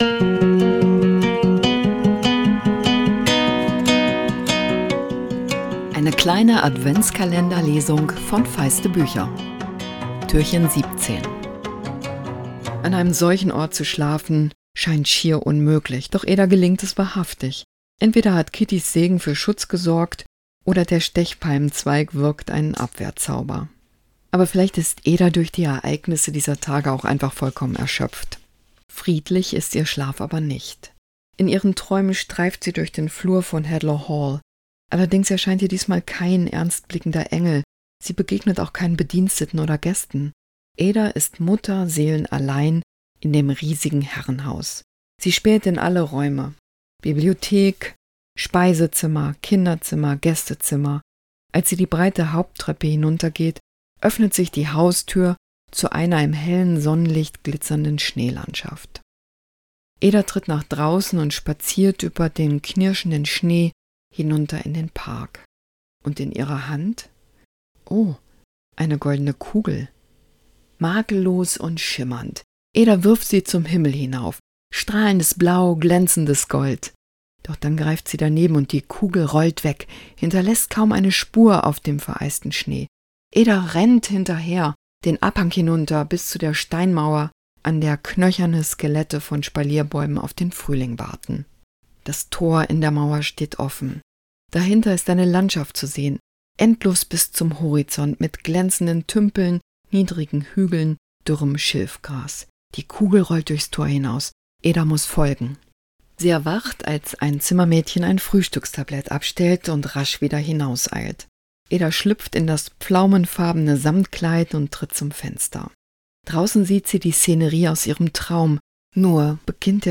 Adventskalender-Lesung 2024! Jess Kidd nimmt euch mit ins Leben des Waisenmädchens Ada Lark, das im Traum eine Vorsehung hat...